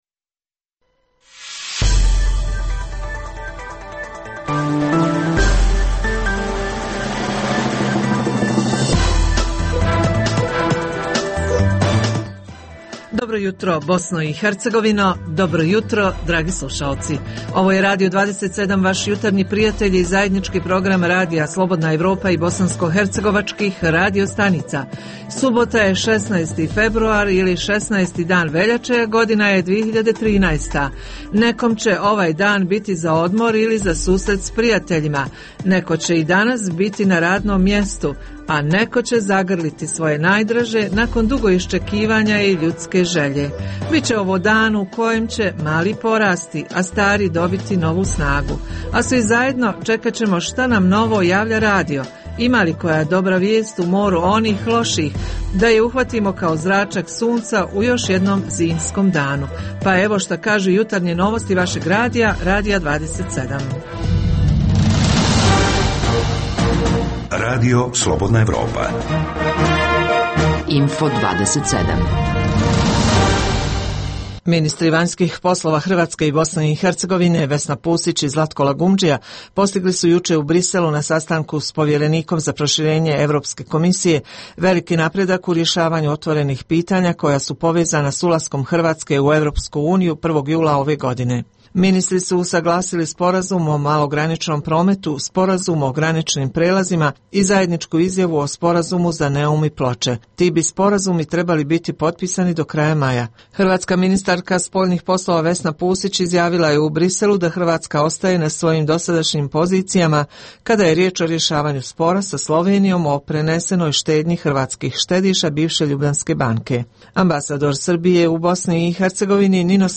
- Uz tri emisije vijesti, slušaoci mogu uživati i u ugodnoj muzici.